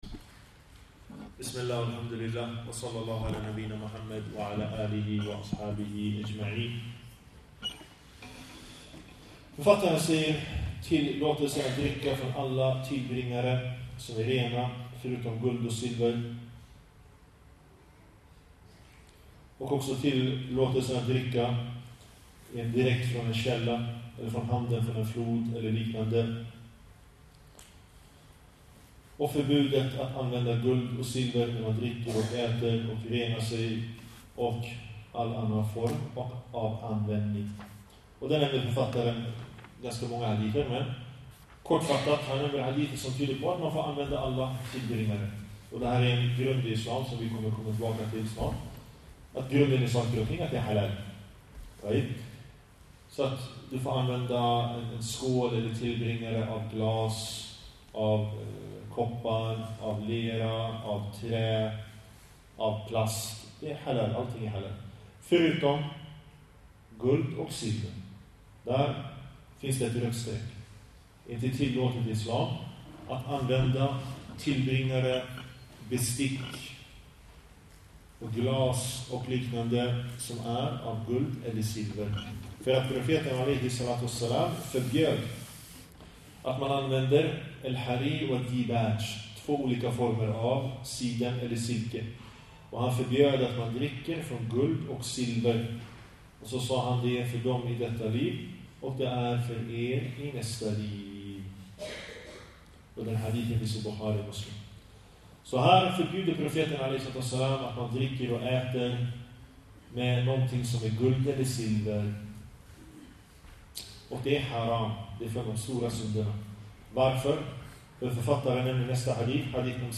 En föreläsning